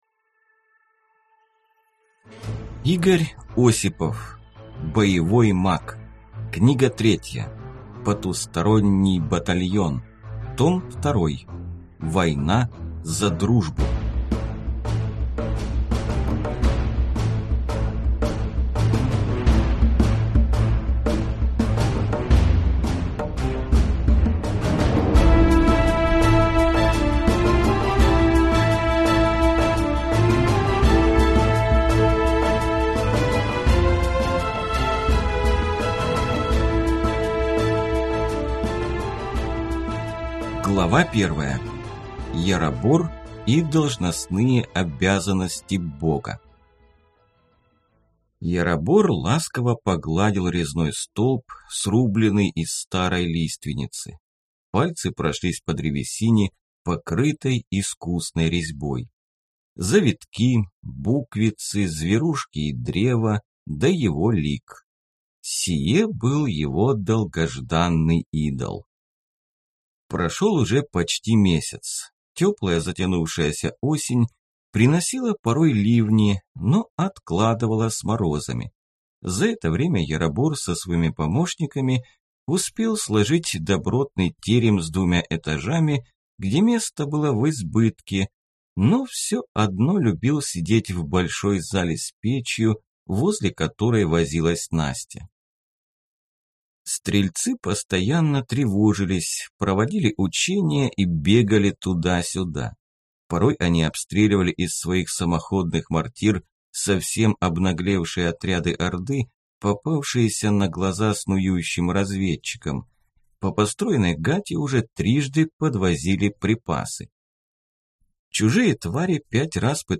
Аудиокнига Потусторонний батальон. Том 2. Война за дружбу | Библиотека аудиокниг